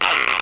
R - Foley 167.wav